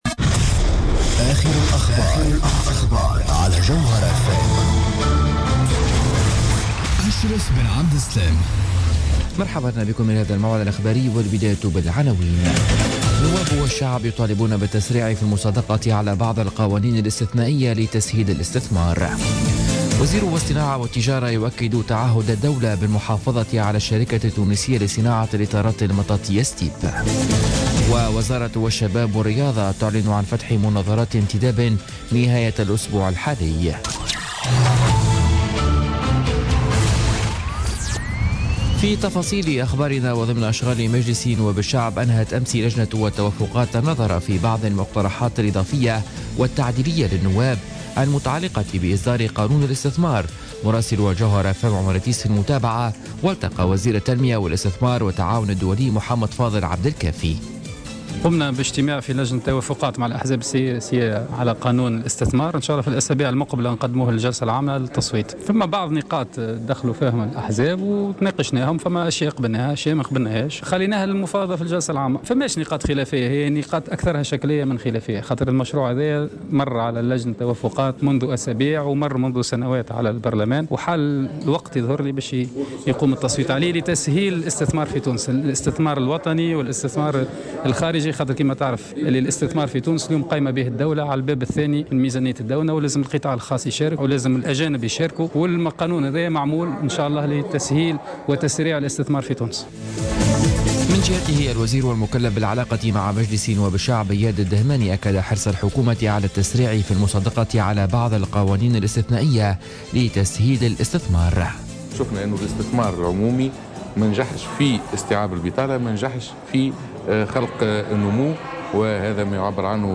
نشرة أخبار منتصف الليل ليوم الخميس 8 سبتمبر 2016